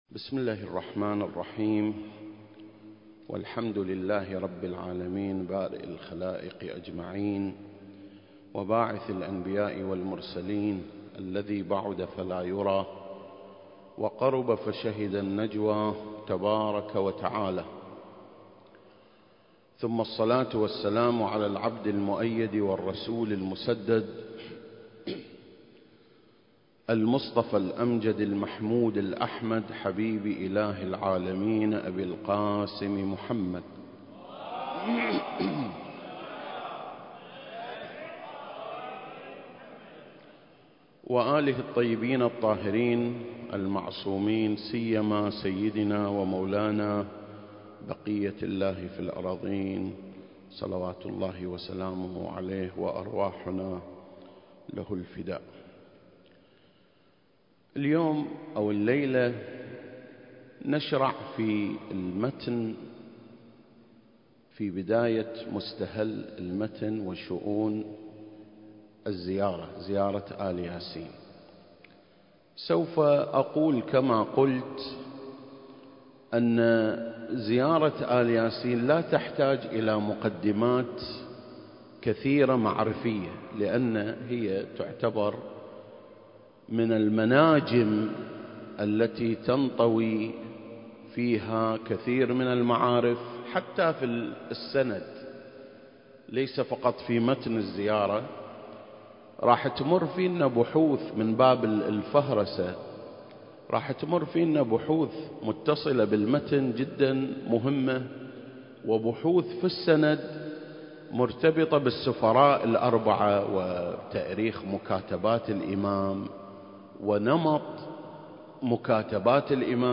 سلسلة: شرح زيارة آل ياسين (٢) - سند الزيارة ومتنها المكان: مسجد مقامس - الكويت